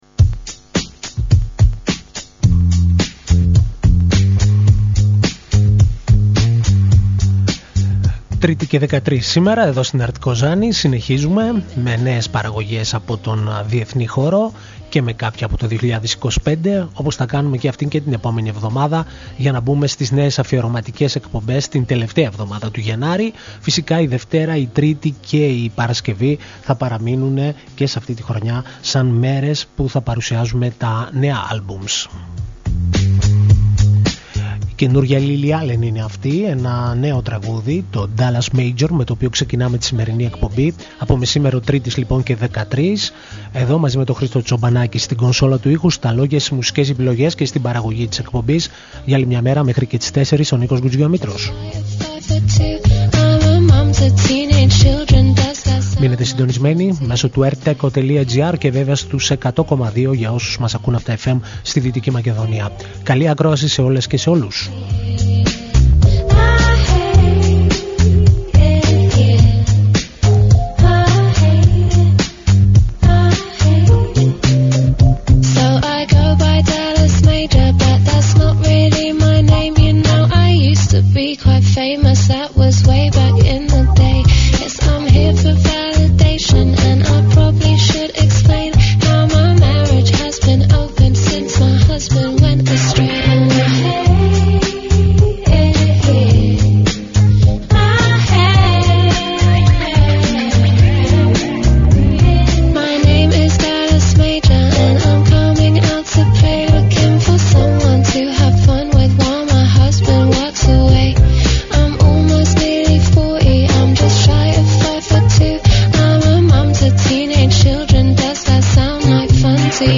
μια επιλογή από τις νέες κυκλοφορίες στη διεθνή δισκογραφία.